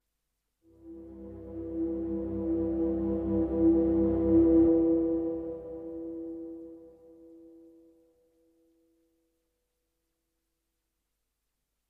Chord only